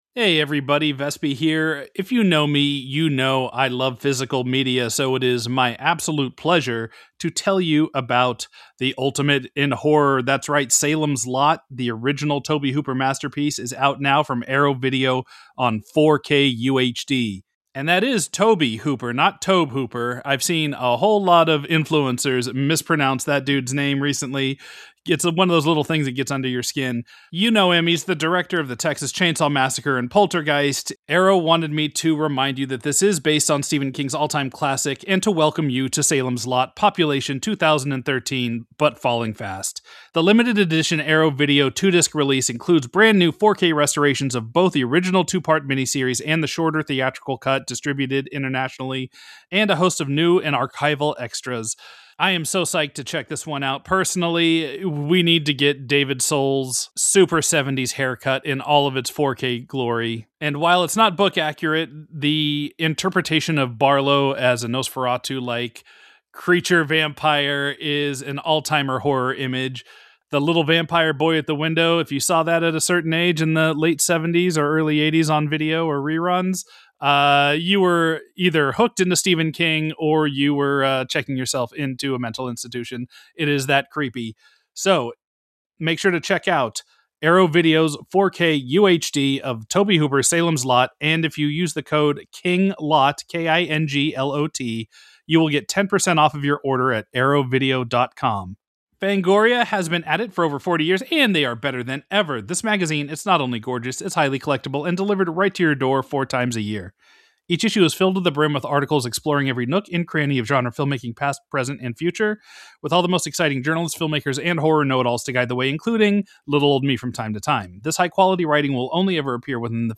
An Interview with William Sadler